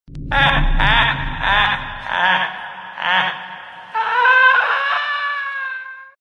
• Качество: 256, Stereo
голосовые
злой смех
зловещие